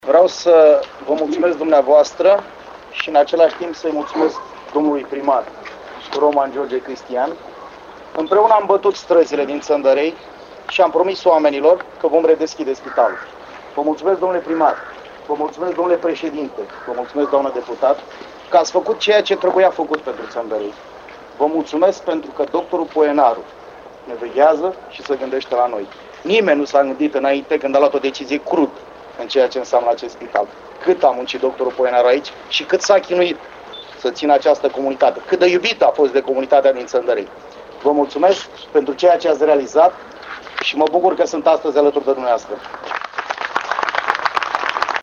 Echipa USL Ialomiţa s-a reunit la Ţăndărei unde, alături de peste 100 de cetăţeni din zonă, a participat la redeschiderea spitalului şi a garantat pentru funcţionarea acestuia.